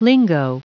Prononciation du mot lingo en anglais (fichier audio)
Prononciation du mot : lingo